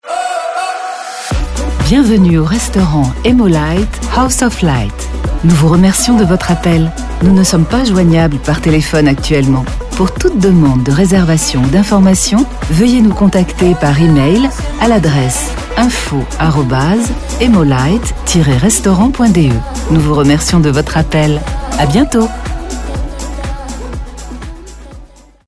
Telefonansagen mit echten Stimmen – keine KI !!!
Um so mehr, freuen wir uns, dass wir gerade die neuen Telefonansagen in 3 Sprachen für die neue Telefonanlage produzieren durften.